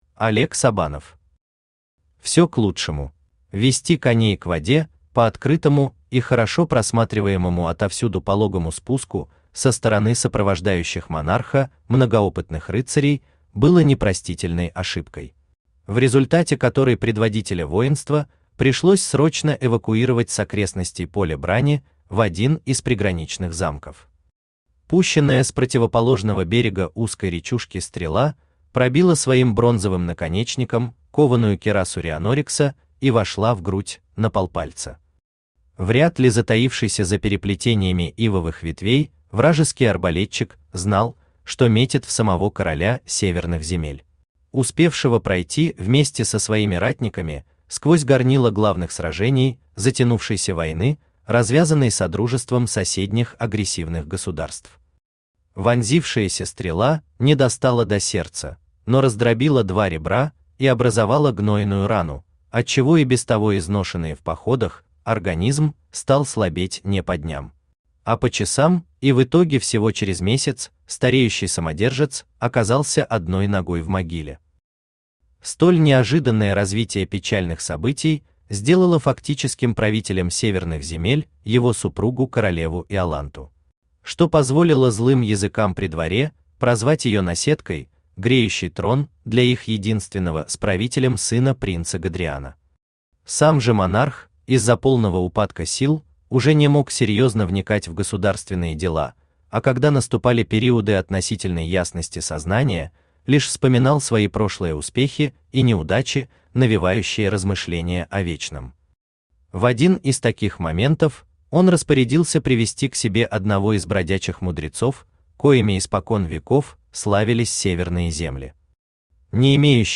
Аудиокнига Все к лучшему | Библиотека аудиокниг
Aудиокнига Все к лучшему Автор Олег Александрович Сабанов Читает аудиокнигу Авточтец ЛитРес.